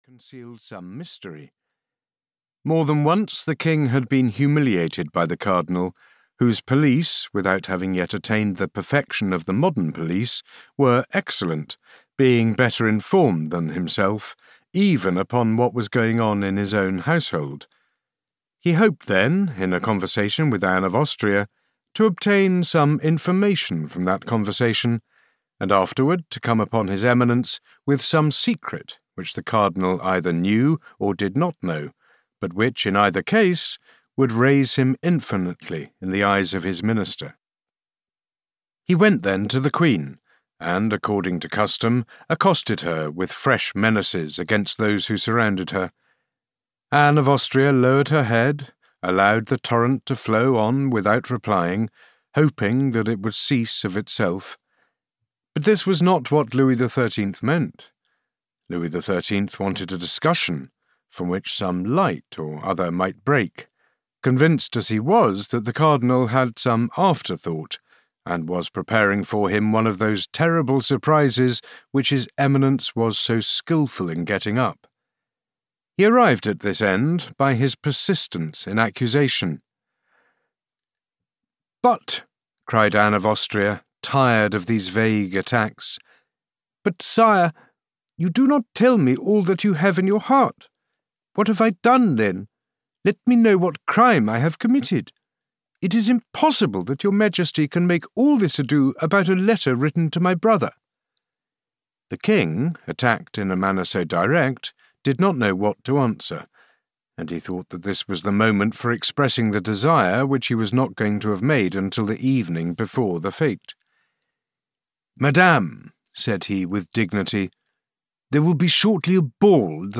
The Three Musketeers II (EN) audiokniha
Ukázka z knihy